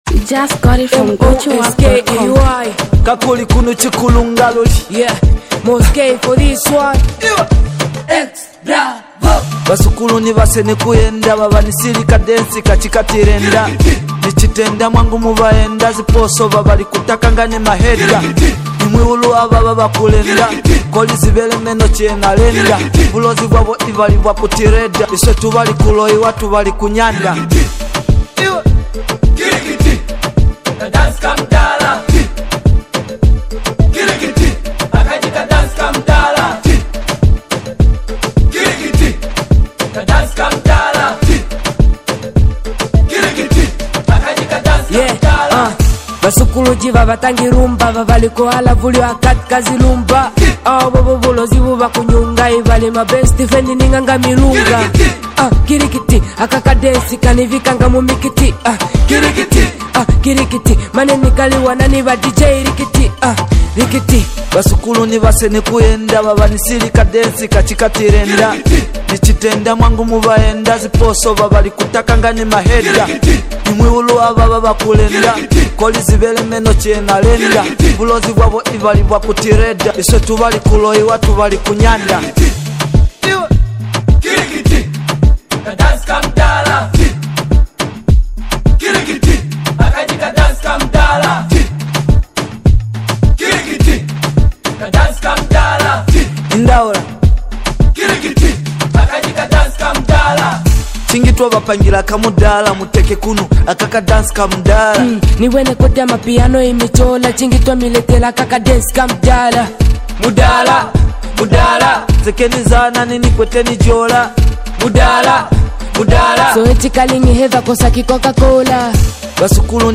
Namibian music